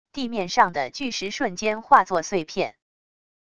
地面上的巨石瞬间化作碎片wav音频